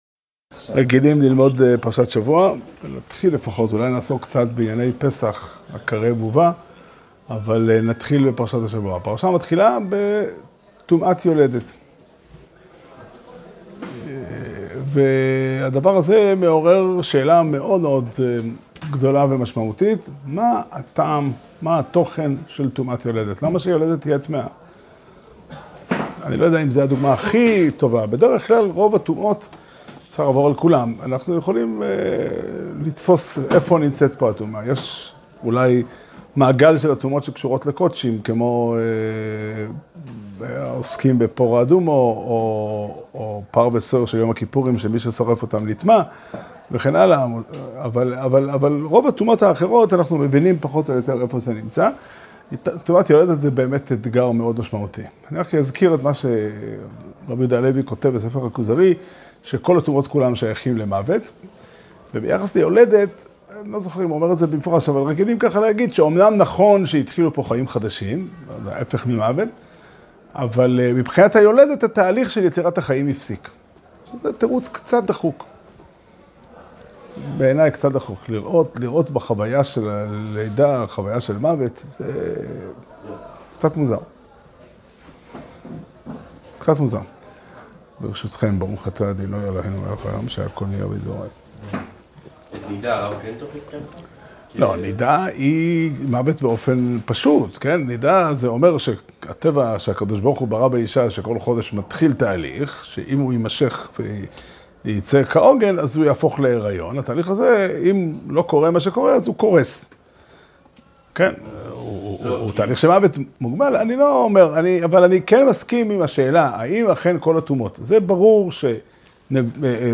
שיעור שנמסר בבית המדרש פתחי עולם בתאריך כ"ח אדר ב' תשפ"ד